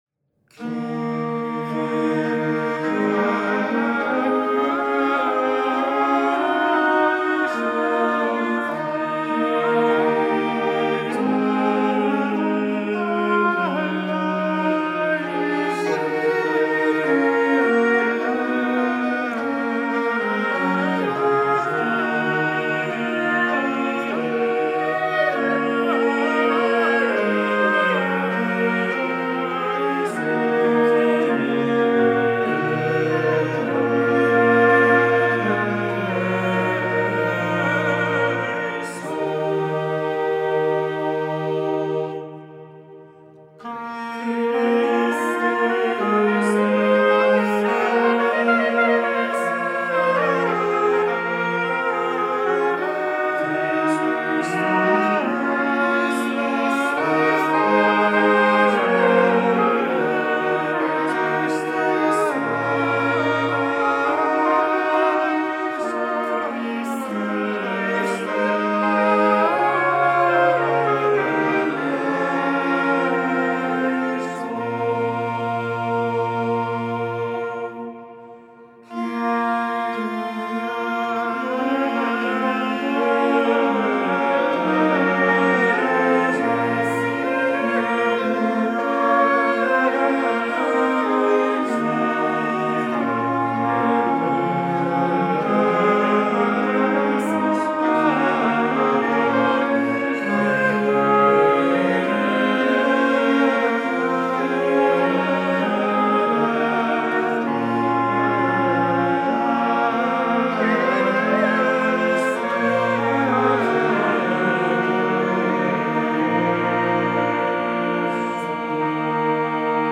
Ensemble Arco Antiqua
Ensemble Arco Antiqua ha presentato una Missa “L’homme Armé” ricca di fascino, con l’uso di strumenti come il cornetto, il trombone, la bombarda e la presenza di ricercate “diminuzioni” , composte grazie all’attento studio dei trattati dell’epoca.
In omaggio all’ambiente mantovano è stato scelto per l’esecuzione un diapason a 466 con temperamento mesotonico 1/4 di comma .